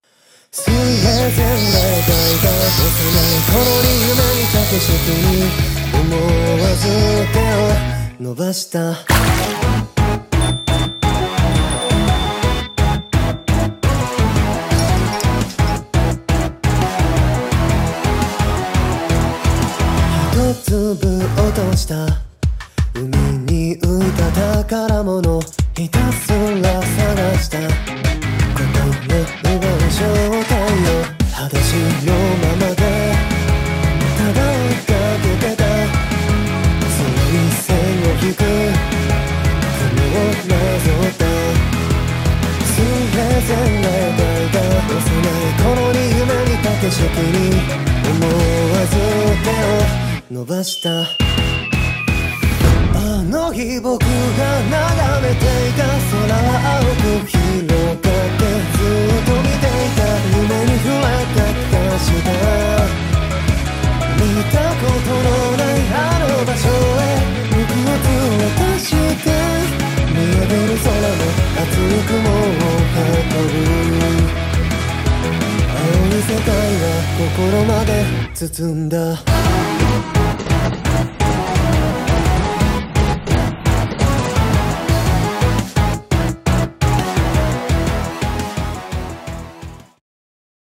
【声劇】サマーデュオ